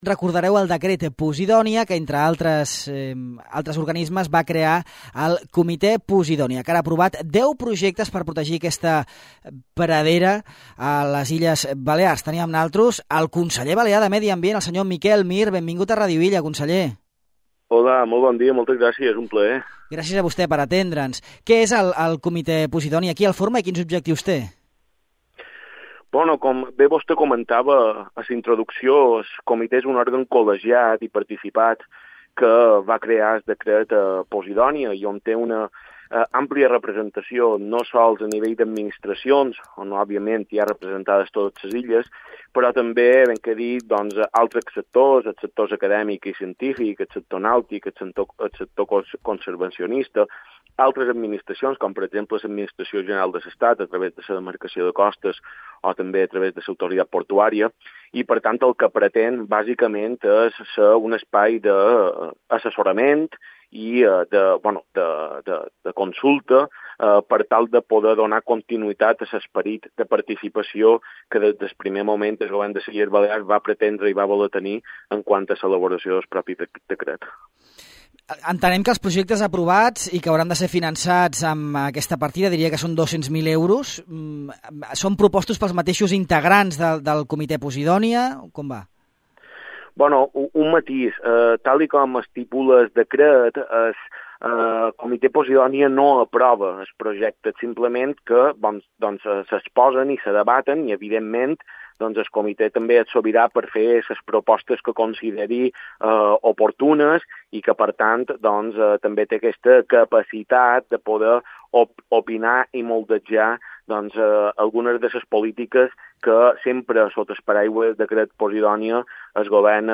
El Comitè de la Posidònia aprovava aquest dilluns els primers deu projectes finançats amb el fons de la posidònia, que actualment disposa de 200.000 €. Parlem d’aquests projectes, així com de la protecció de les praderies de posidònia amb El conseller balear de Medi Ambient, Miquel Mir, que també ens avança el compromís del Govern de les Illes Balears a seguir treballant de manera conjunta amb el Consell de Formentera per a tramitar davant Demarcació de Costes de l’Estat el projecte de regulació de fondejos a tot el litoral formenterer.